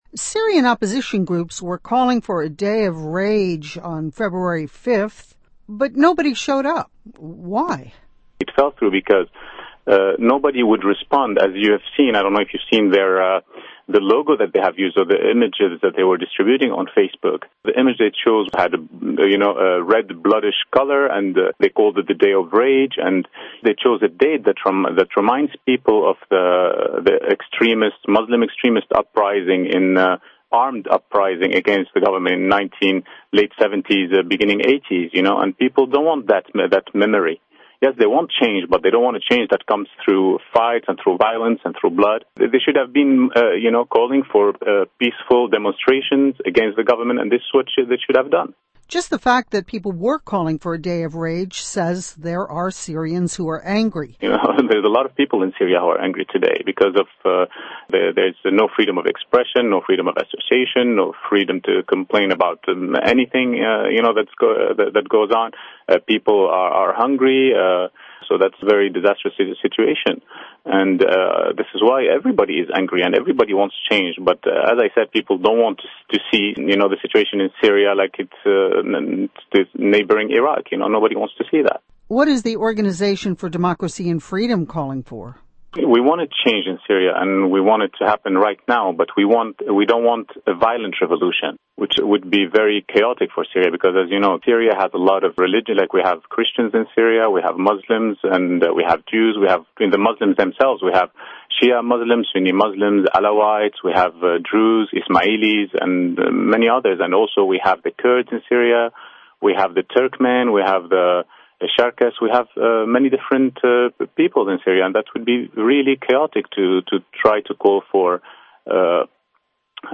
Full interview with Ribal Al-Assad